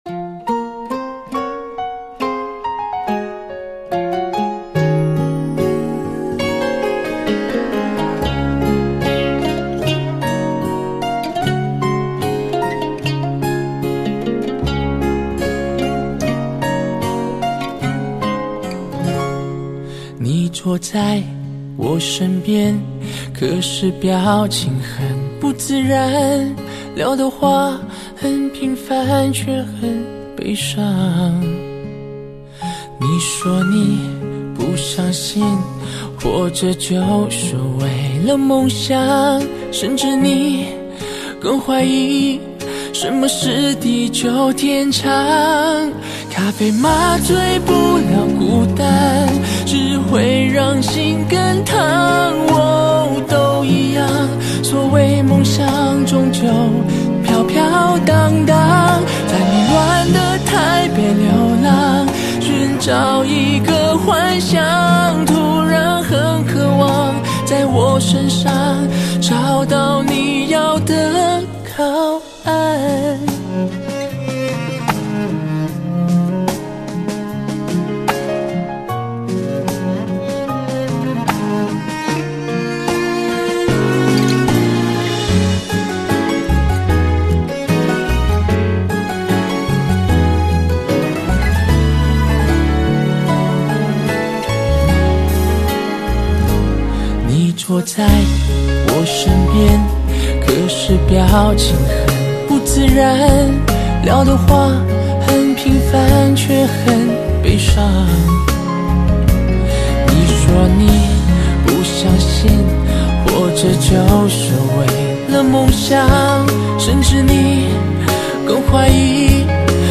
Nu-metal